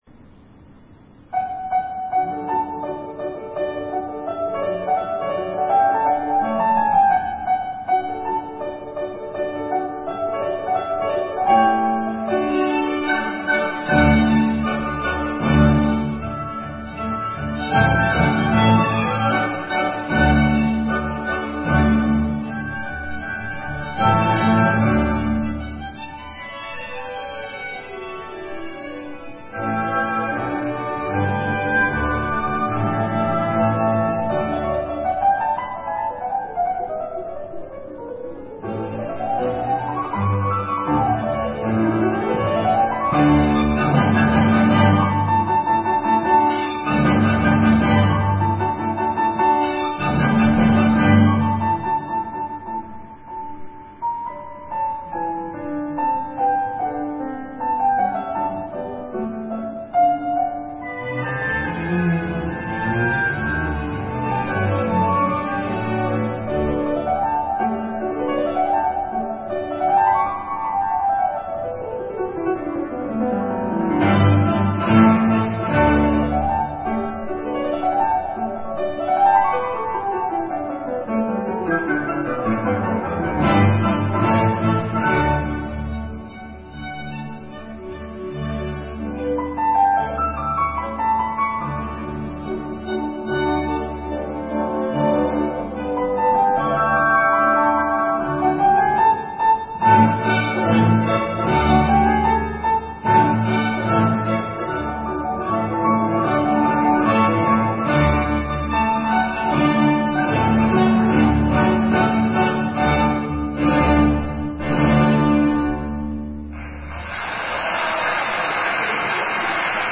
ピアニストの反田恭平がザルツブルク音楽祭デビューを果たしました。
あわててORF（オーストリア放送）のラジオ放送に移って音声だけですが生配信を聴くことができました。
ザルツブルクのモーツァルテウム大ホールでの演奏です。圧倒的な響きでした。
レコーダーをセットして空気録音してみました。戴冠式の３楽章のフィナーレです。